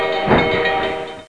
TRAIN.mp3